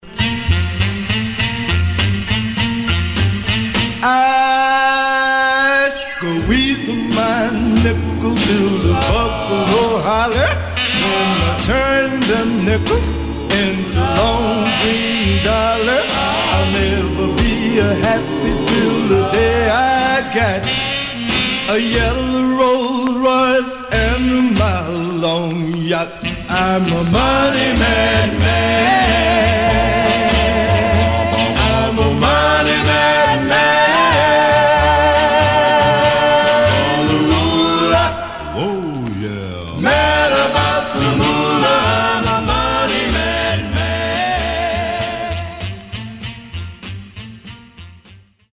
Stereo/mono Mono
Condition Surface noise/wear